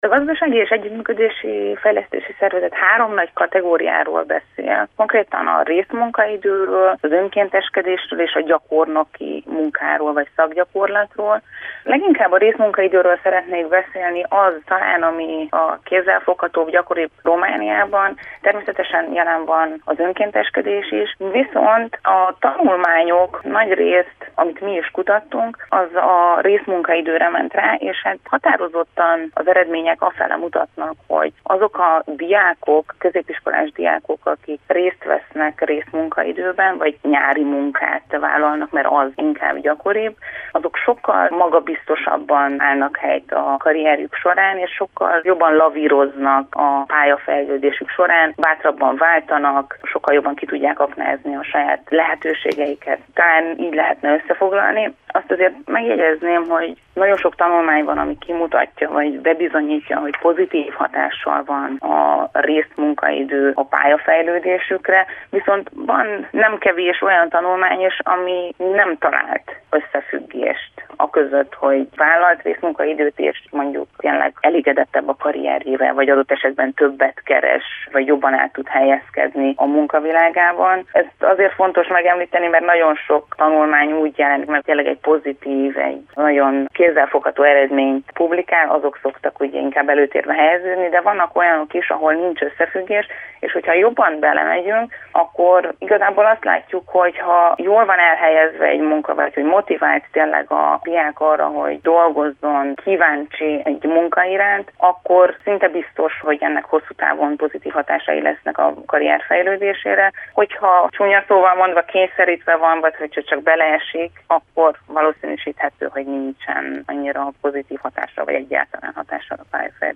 Önkéntesség és részmunkaidő is előnyt jelent a későbbiekben egy tanulmány szerint. Szakembert kérdeztünk.